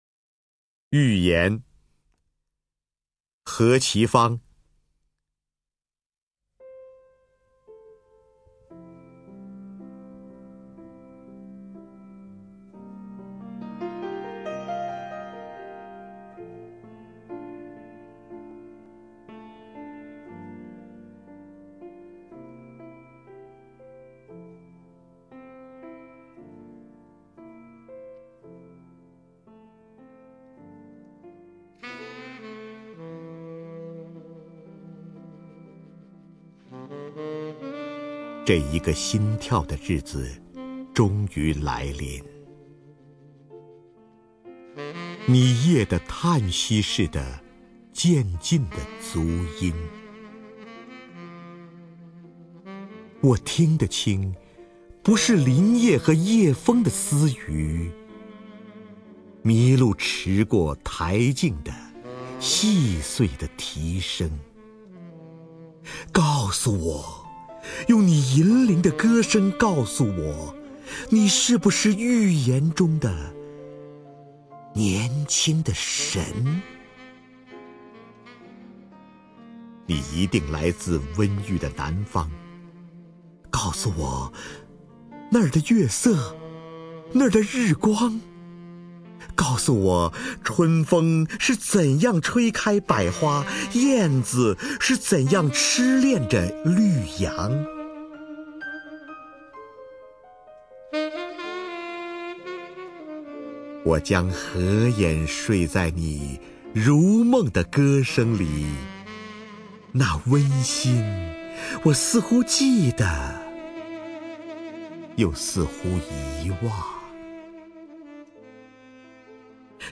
首页 视听 名家朗诵欣赏 瞿弦和
瞿弦和朗诵：《预言》(何其芳)